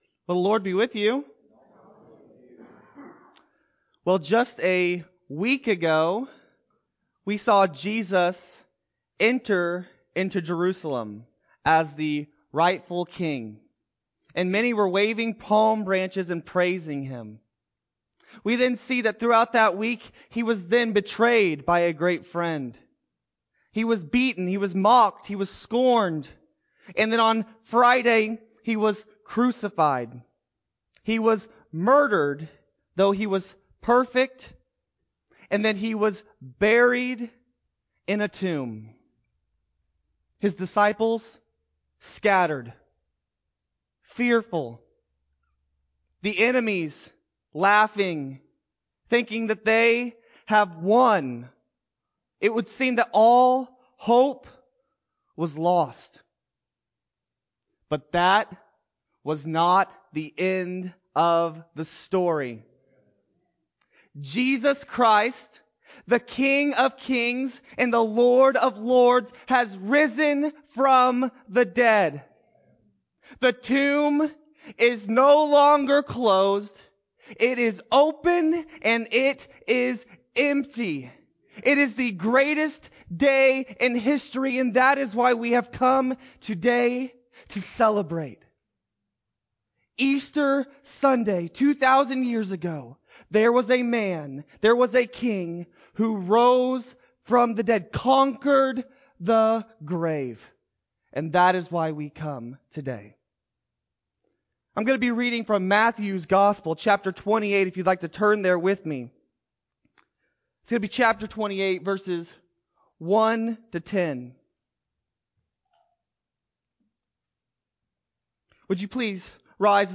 4-4-21-Sermon-CD.mp3